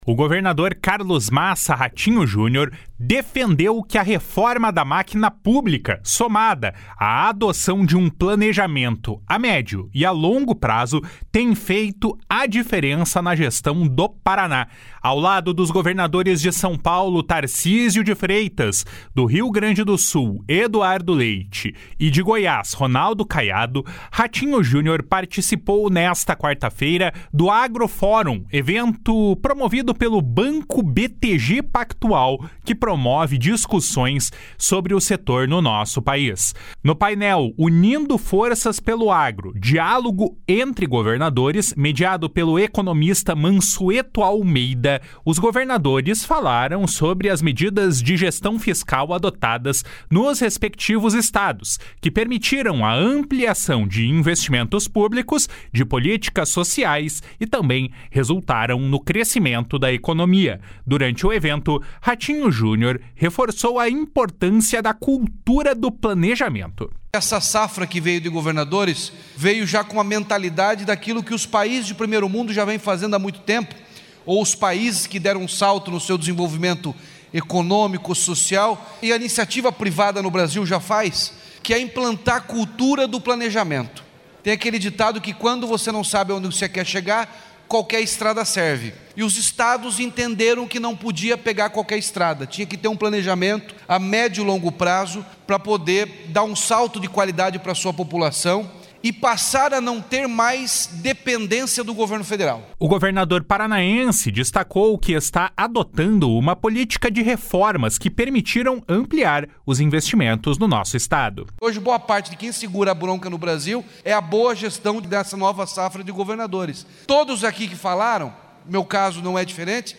O governador paranaense destacou que está adotando uma política de reformas que permitiram ampliar os investimentos no Estado. // SONORA RATINHO JUNIOR //